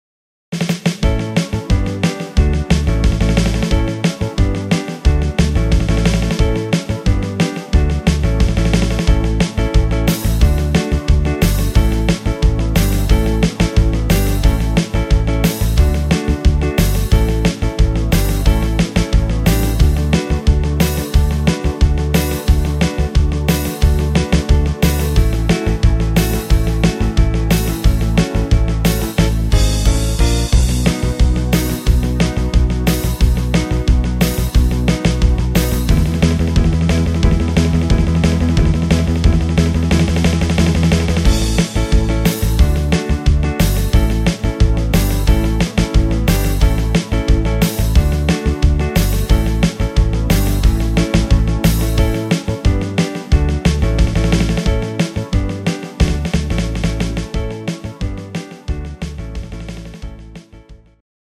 MP3 Playbacks3
inst. Gitarre